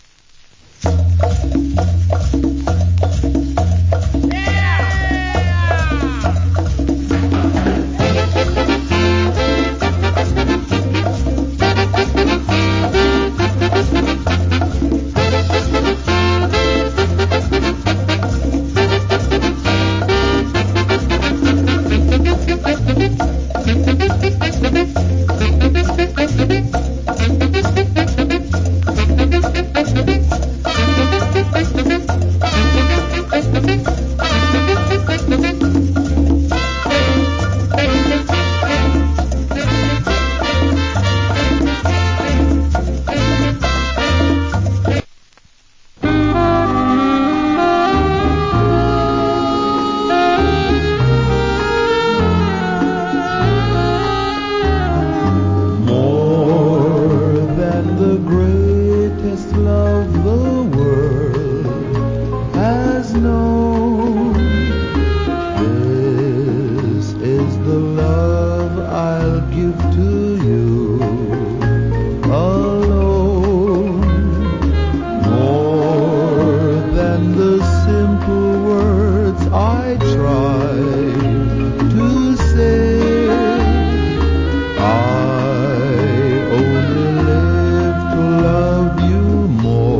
Wicked Calypso Inst. / Slow Vocal.